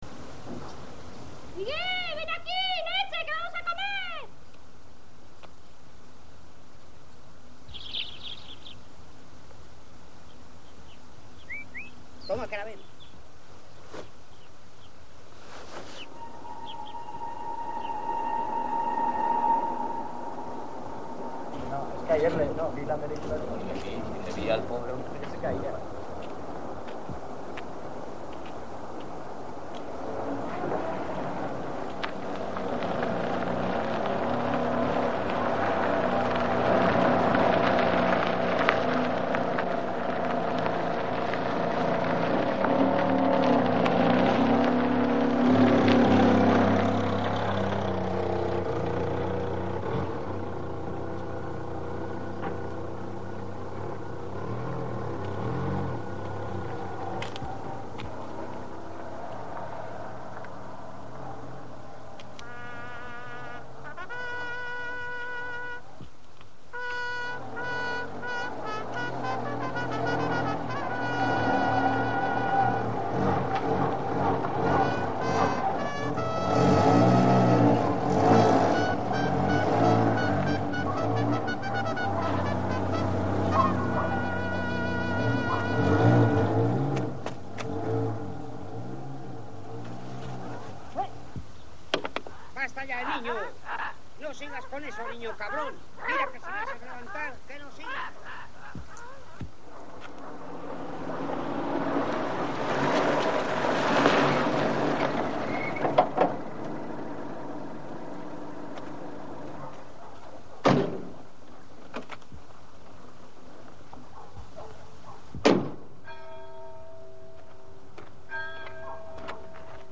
On peut écouter une copie de la bande-son correspondant exactement au plan-séquence (attendre quelques secondes le démarrage de la bande) :
Le coup de feu mortel éclate à 3mn 02 de  l’enregistrement. Il faut être attentif le coup de feu étant judicieusement noyé dans le bruit d’un moteur automobile. Il est suivi d’un solo de trompette puis de la sirène d’une voiture de police.